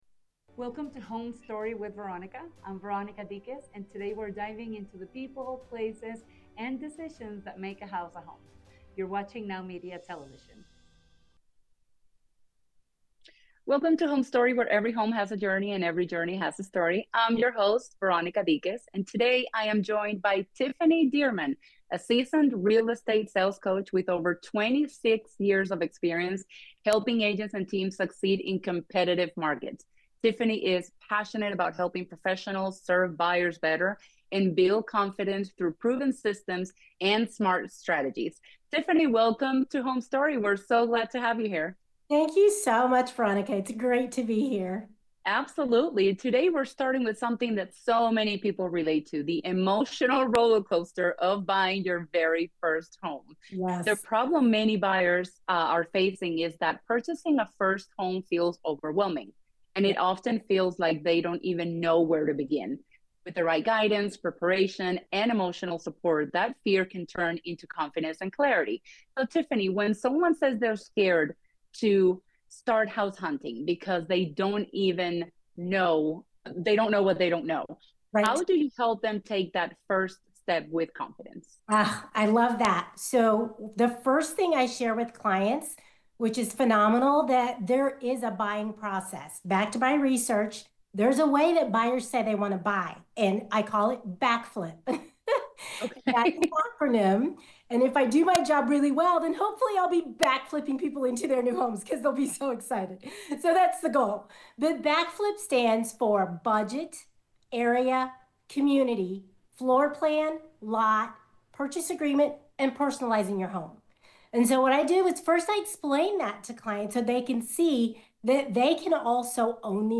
sits down with real estate expert and sales coach